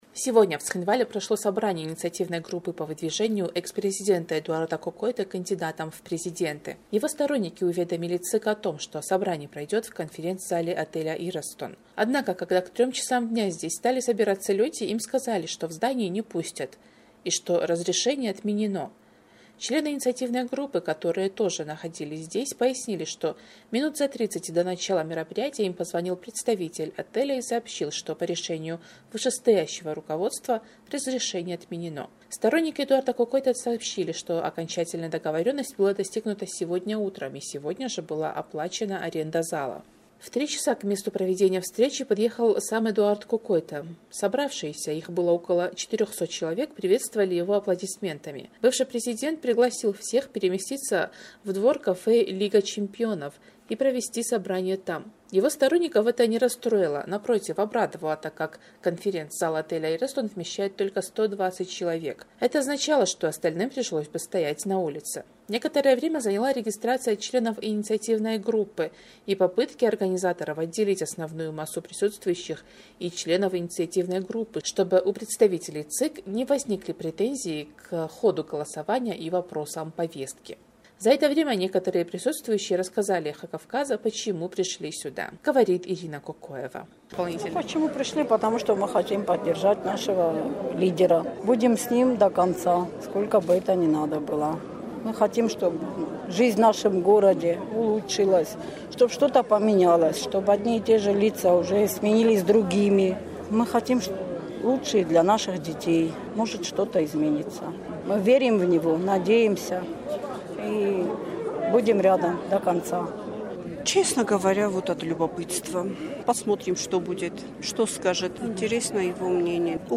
За это время некоторые присутствующие рассказали «Эху Кавказа», почему пришли на собрание: